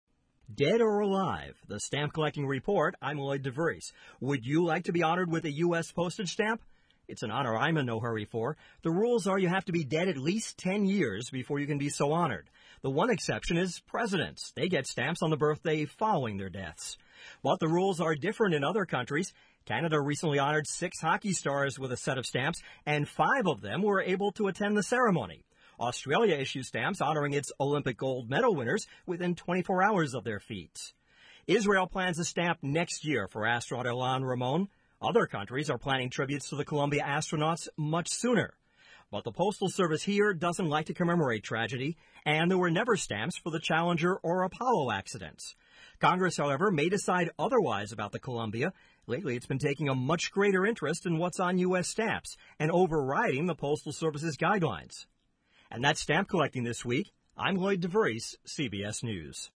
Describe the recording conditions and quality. For broadcast on CBS Radio Network stations March 1-2, 2003: